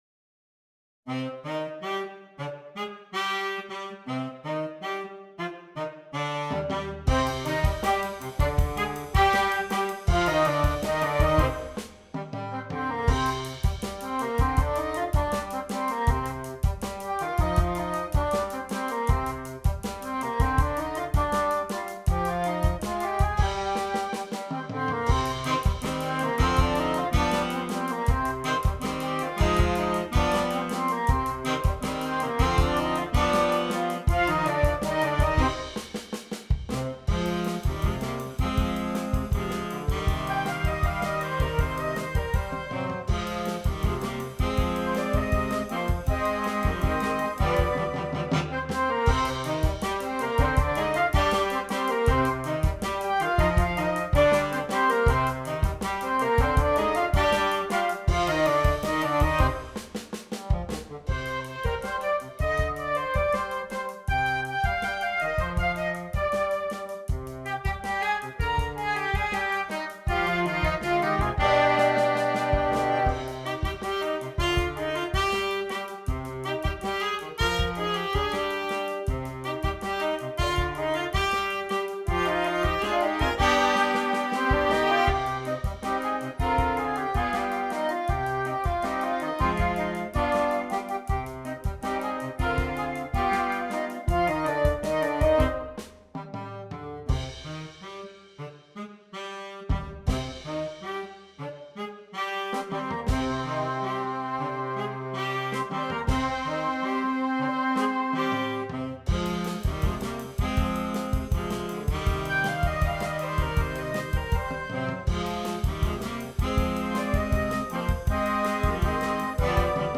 Emerging Jazz Series
There are written out solos included for several parts.